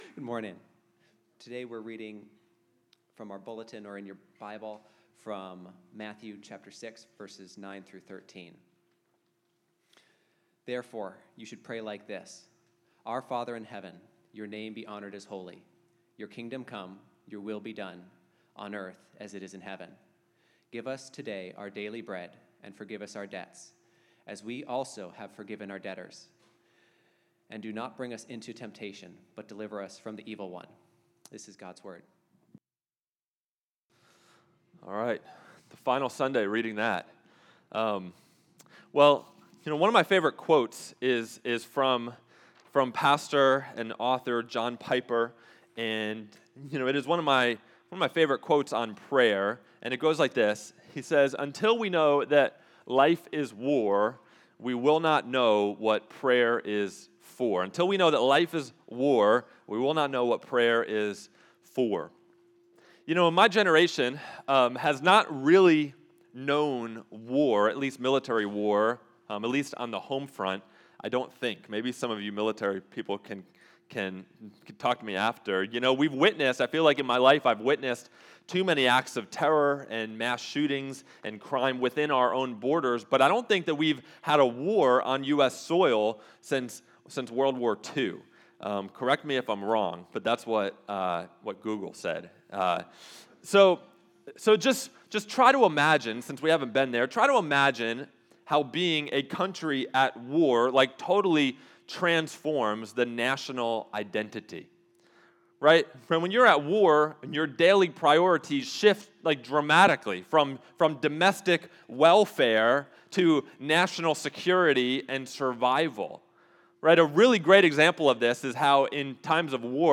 Deliver Us: Prayer as Warfare Scripture Text: Matthew 6:9-15 Date: October 5, 2025 AI Generated Summary: In this sermon, we examine the Lord's Prayer's phrase about forgiveness, recognizing that when we truly look at the itemized list of our sins—hatred, lust, lies, pride—we discover we owe God a massive, unpayable debt. We learn that God cannot simply wink at our sins but instead absorbed the full penalty himself through Christ's death on the cross, offering us costly forgiveness when we humbly confess and repent. We understand that the proof we've truly received God's forgiveness is our ability to forgive others from the heart, no matter how deeply they've wounded us, because nothing they've done to us compares to what God has forgiven us of.